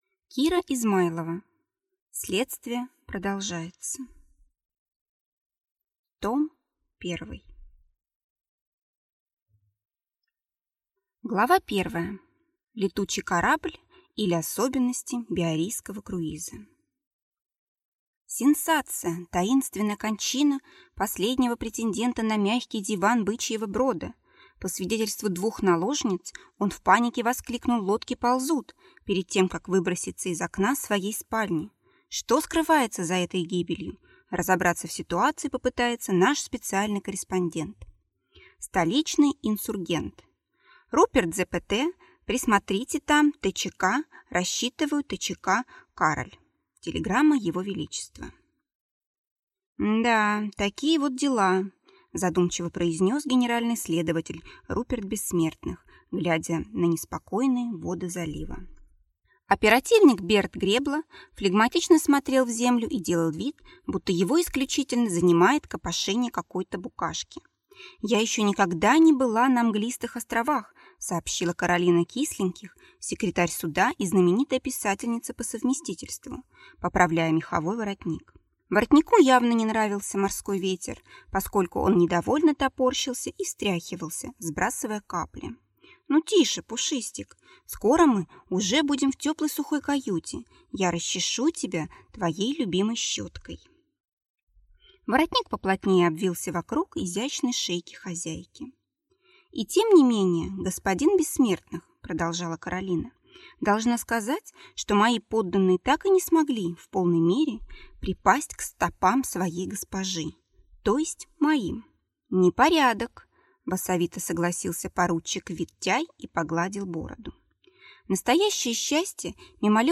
Аудиокнига Следствие продолжается. Том 1 | Библиотека аудиокниг